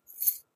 keys.ogg